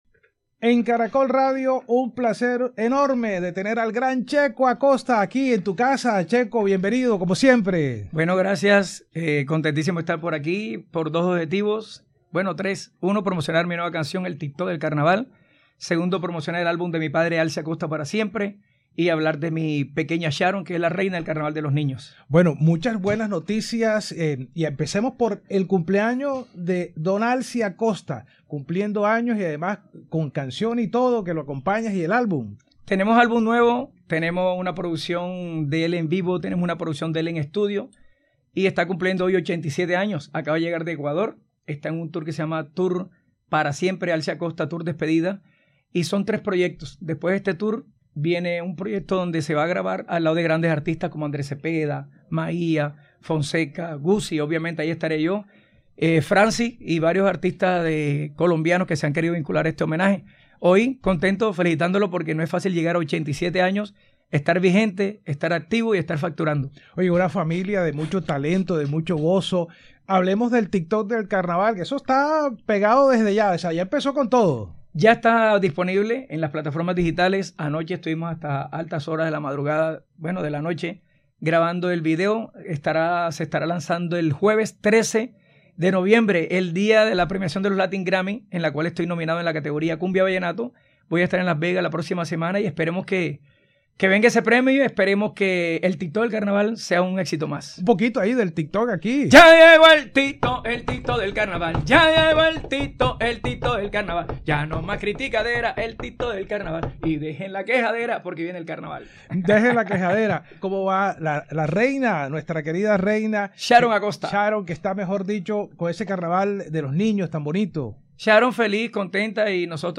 Mi papá grababa, le pagaban por esa producción y ya. Eran pagos únicos. No había ningún tipo de retribución posterior”, explicó Checo en entrevista con Caracol Radio.